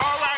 Metro Vox 1.wav